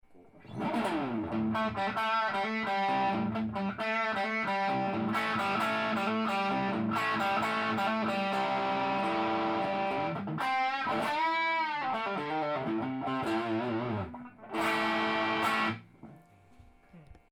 試しにオーバードライブをかけて弾いてみました。